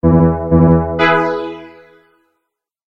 じっくりと深い味わいを持ちながらも派手さのないシンプルな短い音です。
このサウンドは、ストリングス楽器が奏でる美しい音色が特徴であり、聴く者に穏やかな感覚を与えます。